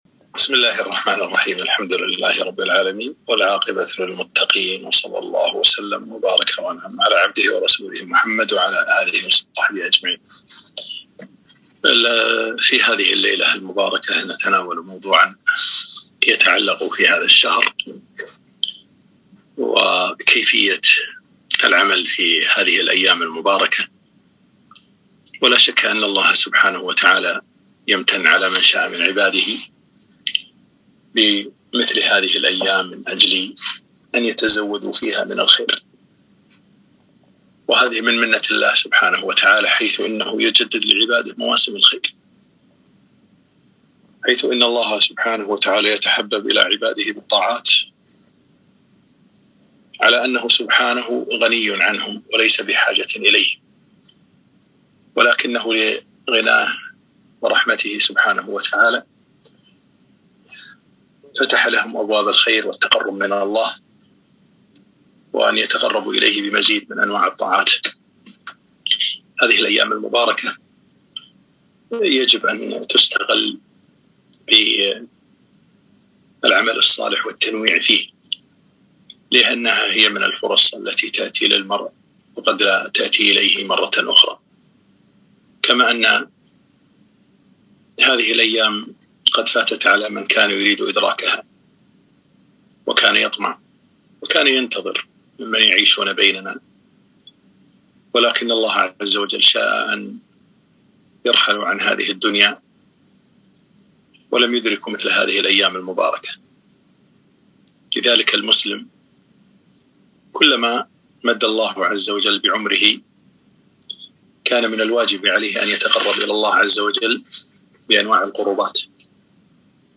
محاضرة قيمة - مشروعك الرمضاني